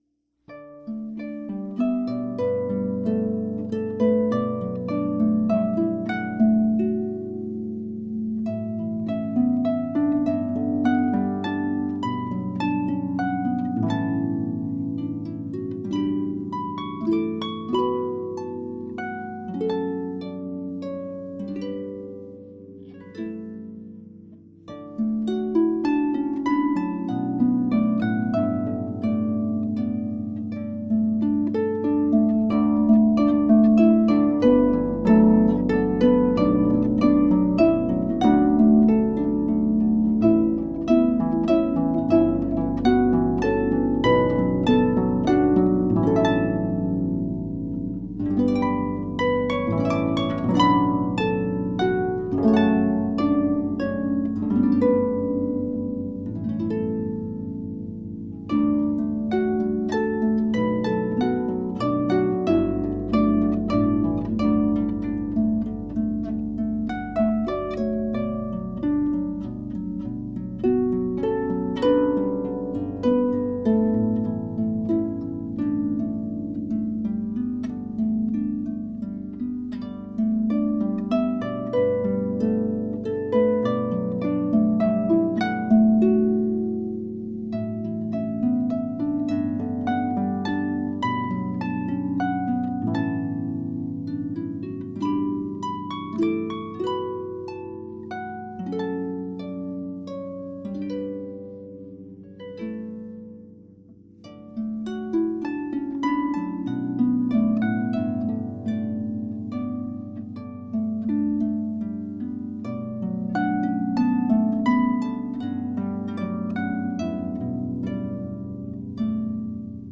Pedal Harp
In my arrangements, you'll hear a mix of celtic, folk, new age, and classical styles.